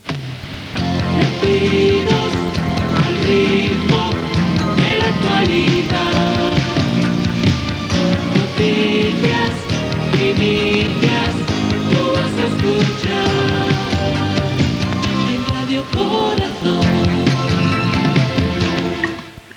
Indicatiu de l'emissora.